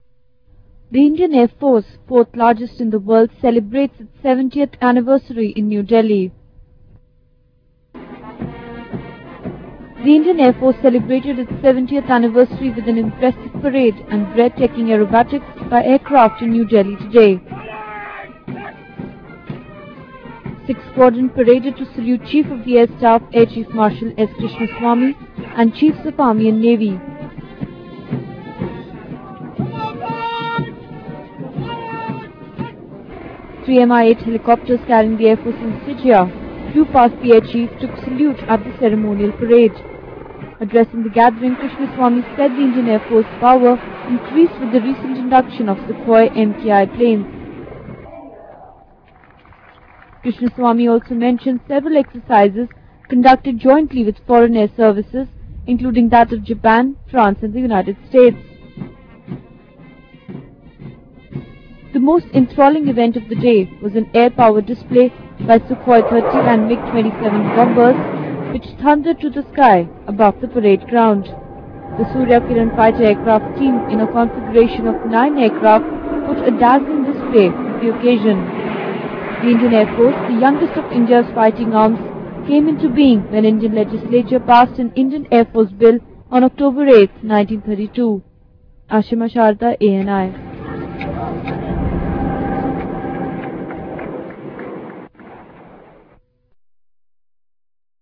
Suryakiran aircraft perform aerobatics as part of the Air Force Day celebrations in New Delhi on Tuesday.